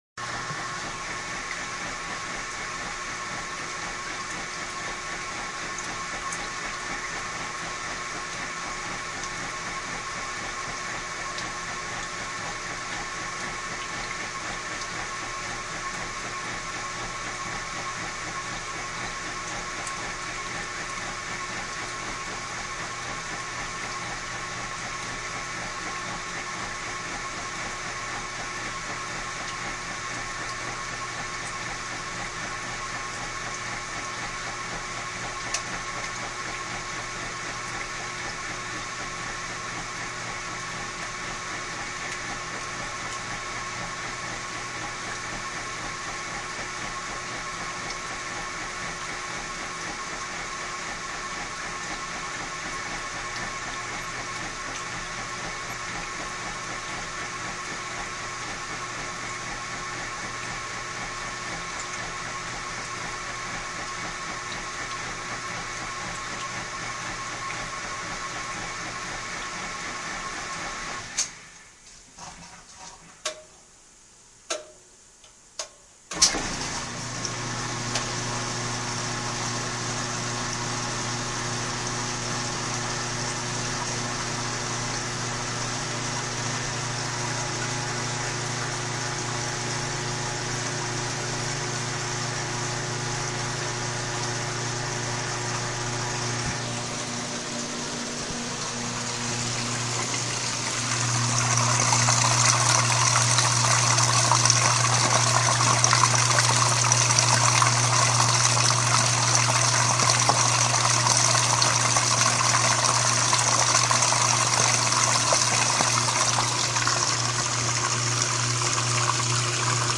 洗衣机全循环
描述：洗衣机在长时间的循环中运行，包括近距离记录水充满机器的盆子和旋转时的嗡嗡声。
Tag: 飞溅 垫圈 洗衣机